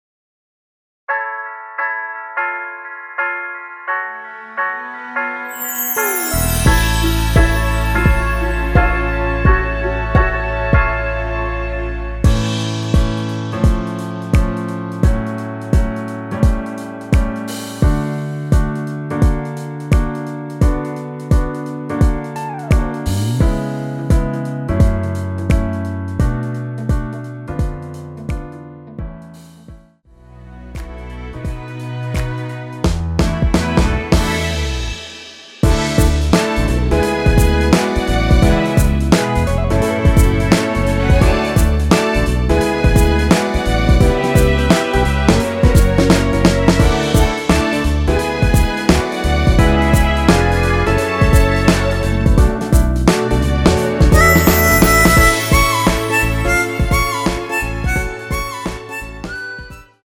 원키에서(+1)올린 MR입니다.
◈ 곡명 옆 (-1)은 반음 내림, (+1)은 반음 올림 입니다.
앞부분30초, 뒷부분30초씩 편집해서 올려 드리고 있습니다.
중간에 음이 끈어지고 다시 나오는 이유는